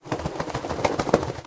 wolf_shake.ogg